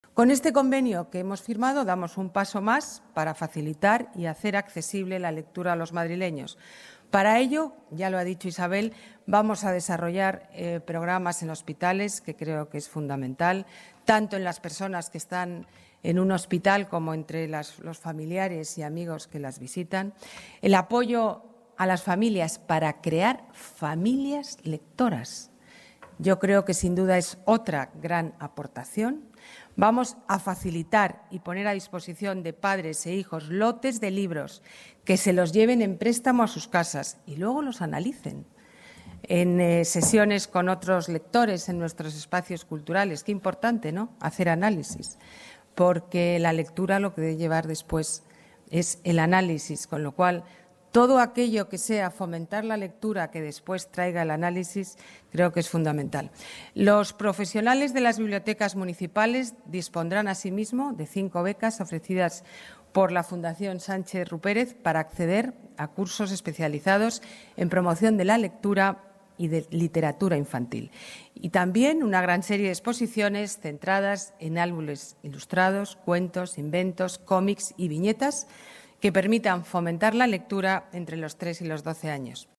Nueva ventana:Declaraciones de la alcaldesa Ana Botella: Convenio Casa Lector